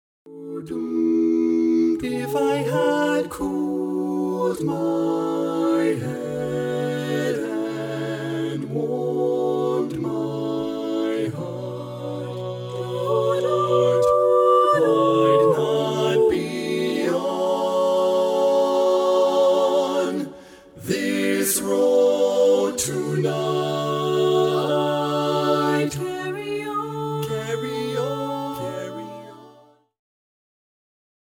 • Full Mix Track
• Accompaniment Track